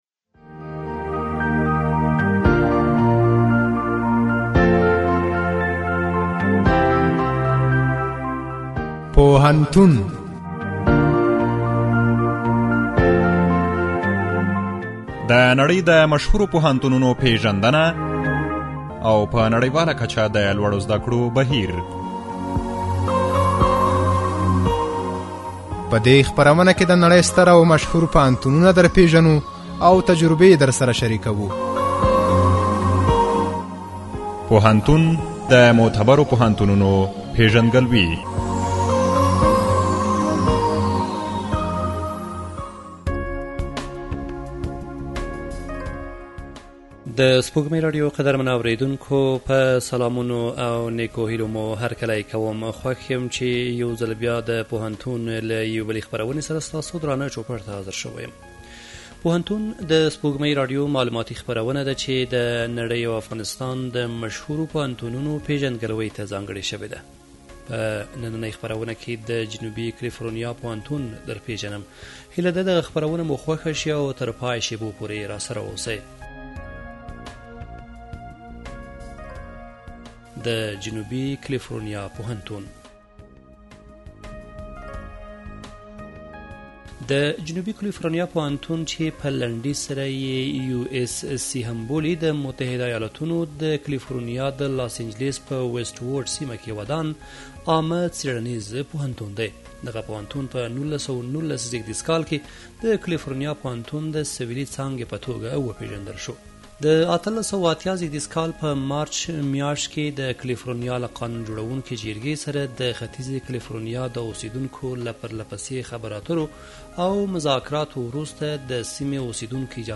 پوهنتون، د سپوږمۍ راډیو معلوماتي خپرونه ده، چې د نړۍ او هېواد دولتي او خصوصي پوهنتونونو پېژندګلوۍ ته ځانګړې شوې